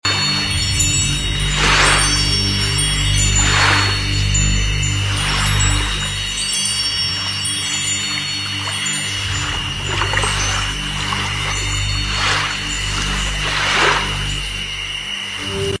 777hz. Sound Healing. Remove blockages sound effects free download